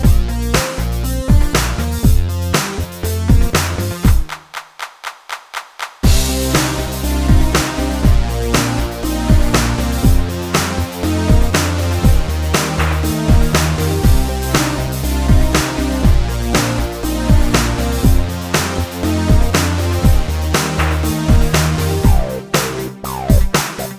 No Backing Vocals No Vocoder Pop (2010s) 3:25 Buy £1.50